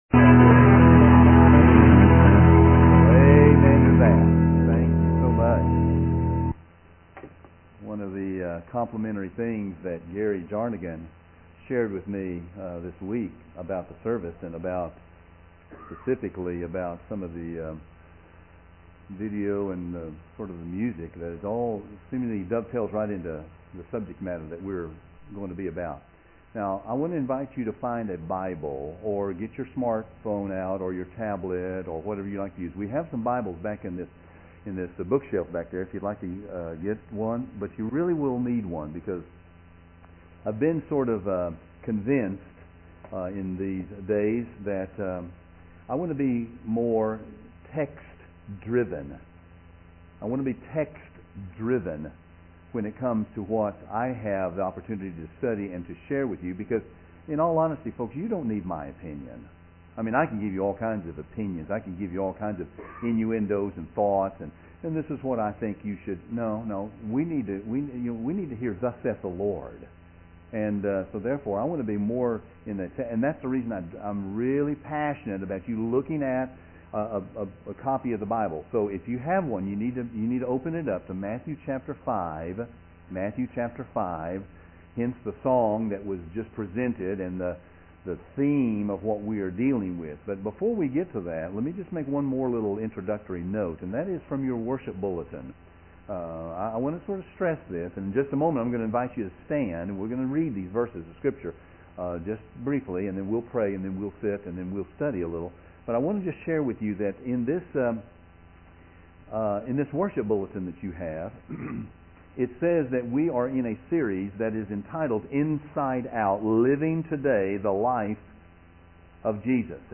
A Study Through The Sermon On The Mount